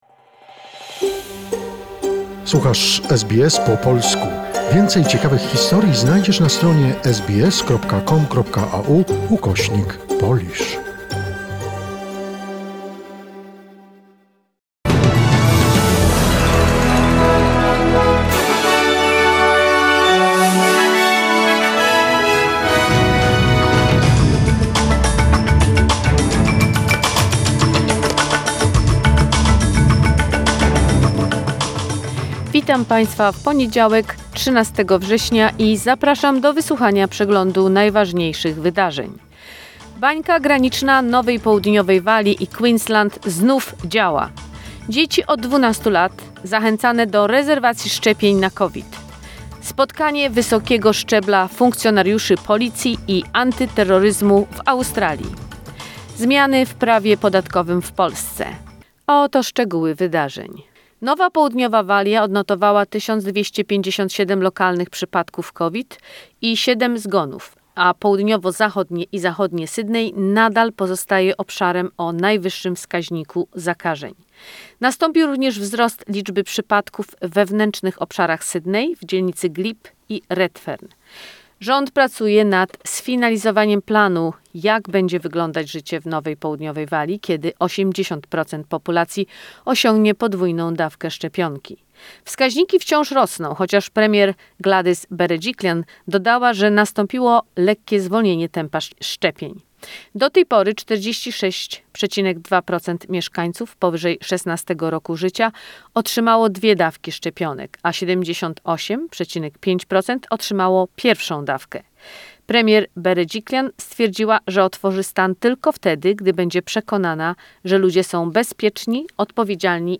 SBS News in Polish, 13 September 2021